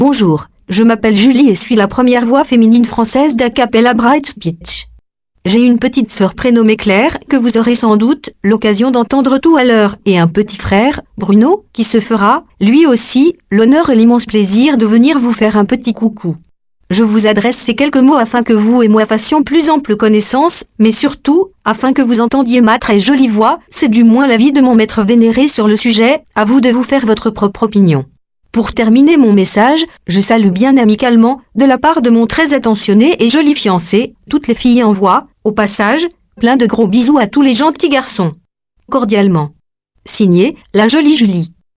Texte de démonstration lu par Julie, première voix féminine française d'Acapela Infovox Desktop Pro